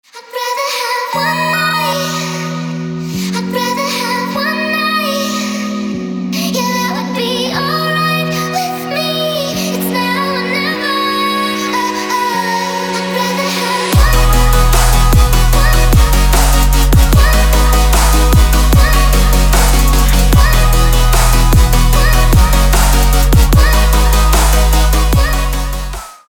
• Качество: 320, Stereo
женский вокал
dance
Electronic
EDM
club
vocal